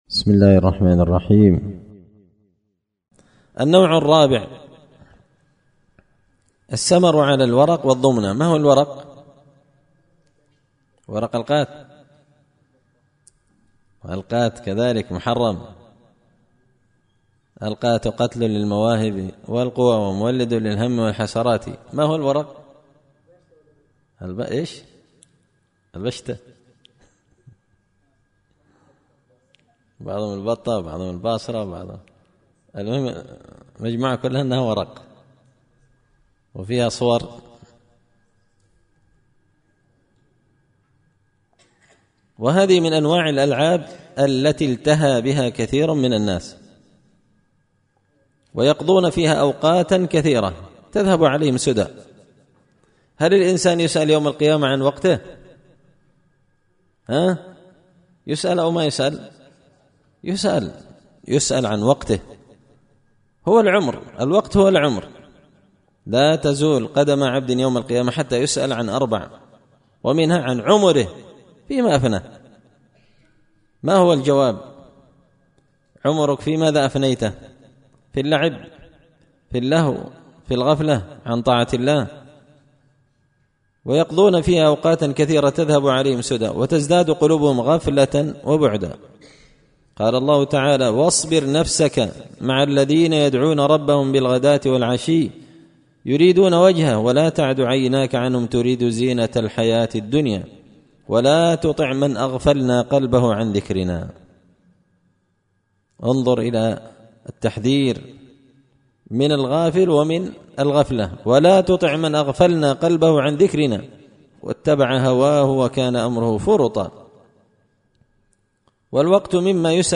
إمتاع النظر بأحكام السمر والسهر ـ الدرس الثامن عشر